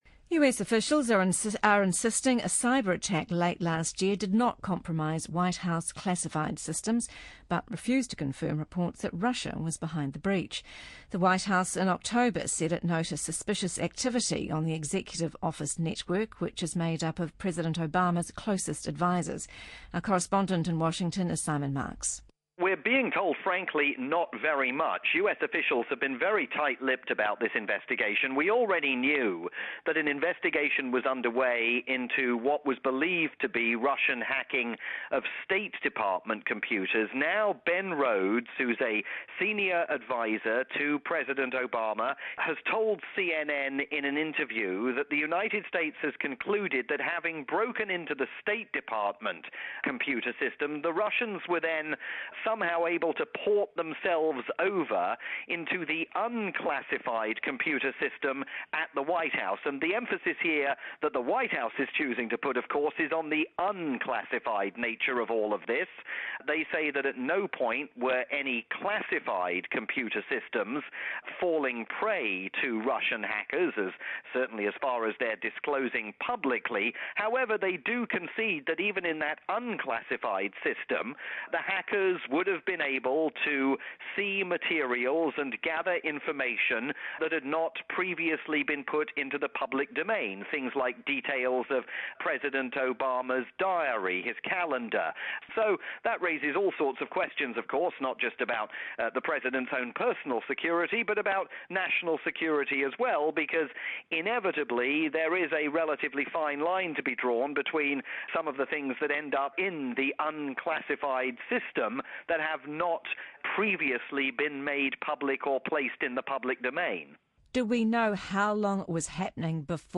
the nightly news program on Radio New Zealand.